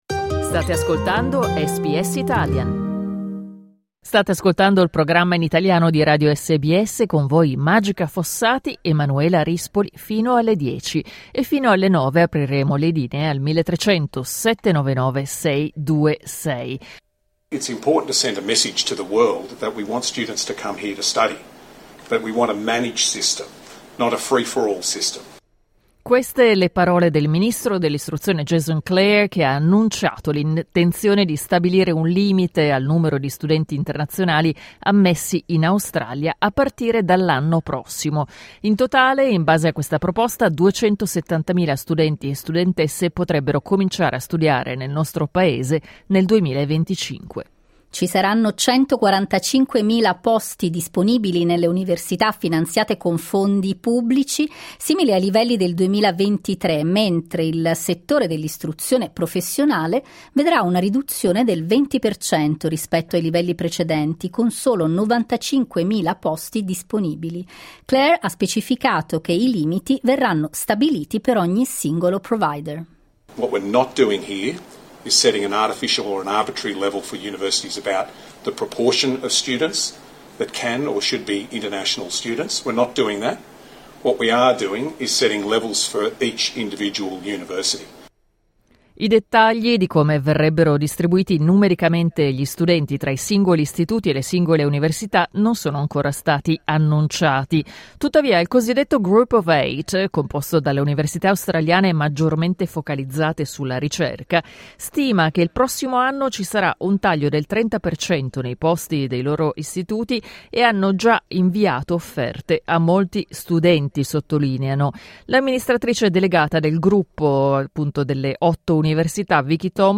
Il governo federale ha proposto un limite al numero di studenti internazionali in Australia, ma le grandi università storcono il naso. Ne abbiamo parlato con due docenti universitari di origine italiana.